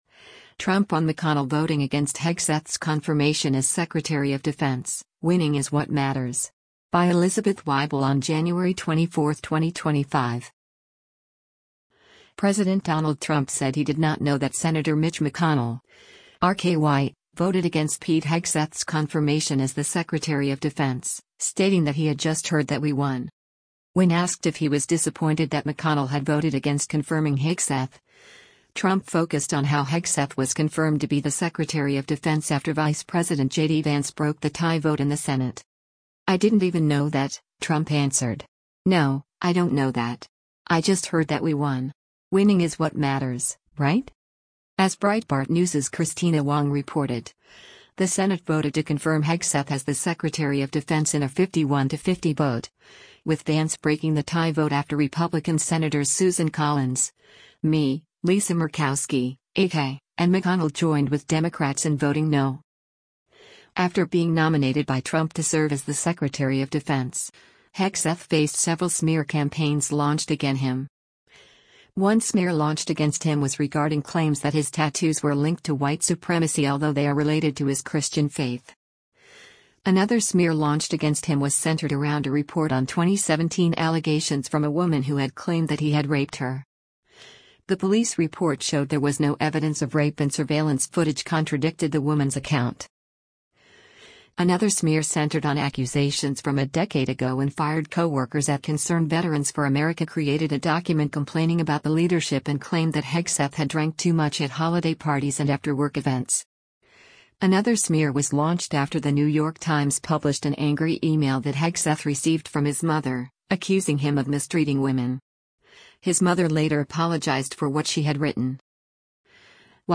US President Donald Trump speaks during a fire emergency briefing at Station 69 in Pacific